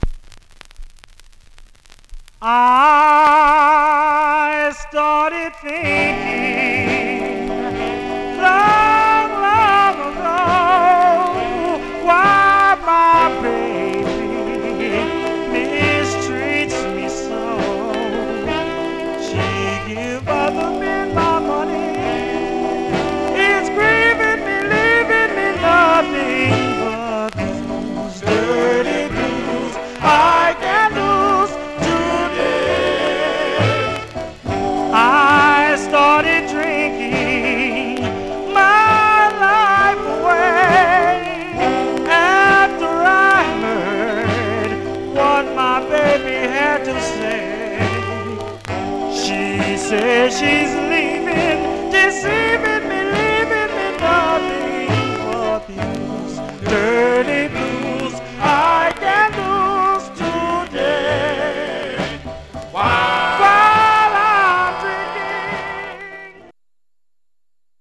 Some surface noise/wear
Mono
Male Black Group Condition